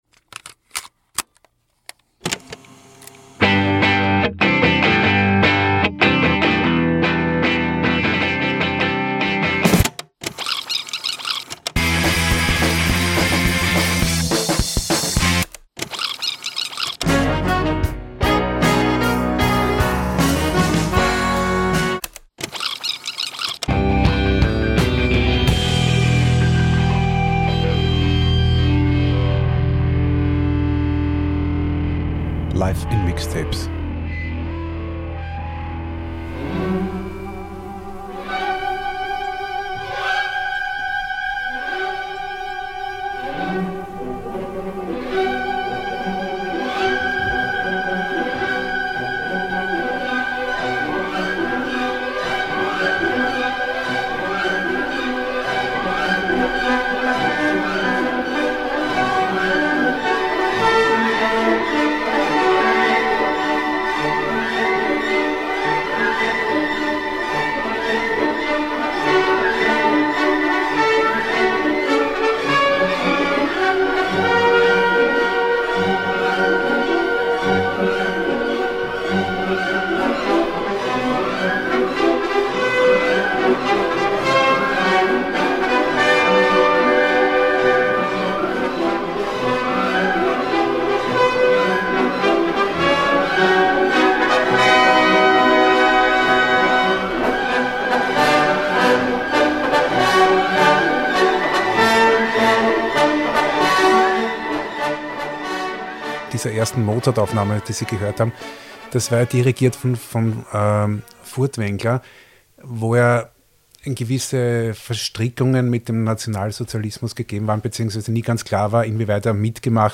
Teil II unseres Gesprächs mit Prof. Konrad Paul Liessmann über Plattenspieler, Plattensammeln und Musikhören.